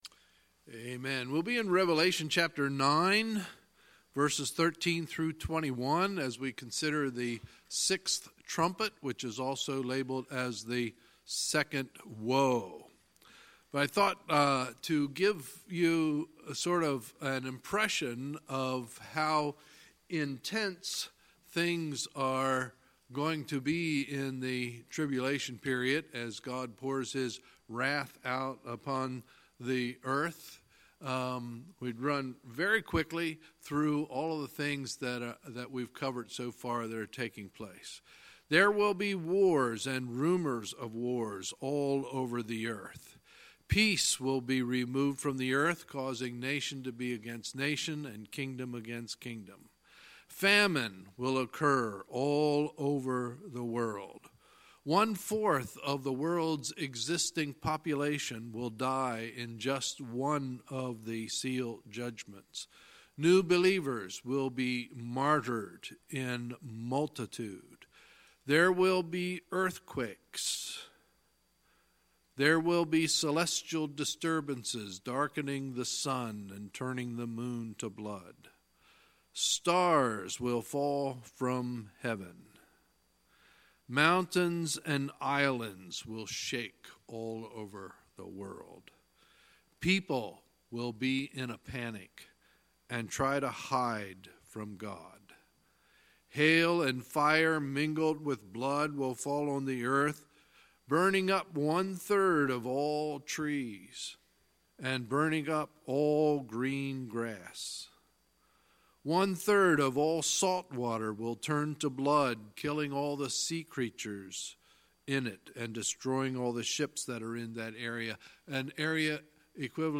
Sunday, February 10, 2019 – Sunday Evening Service